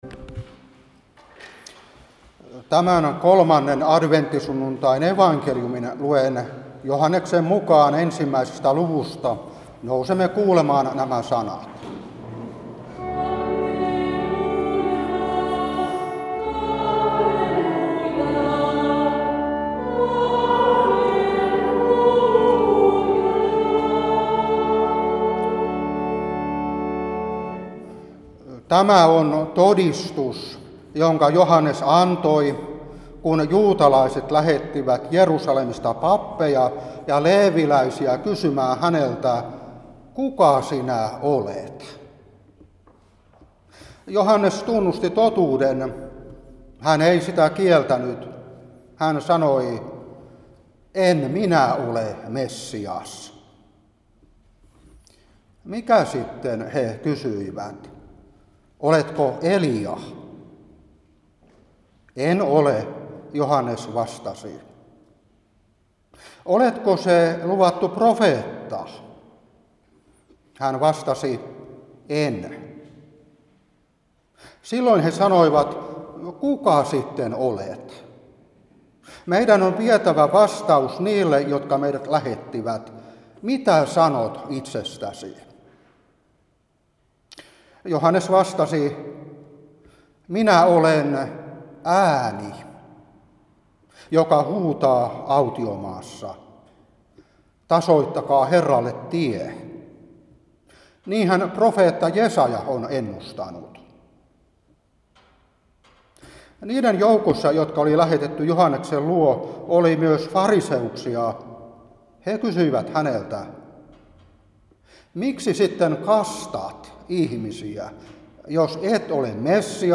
Saarna 2023-12.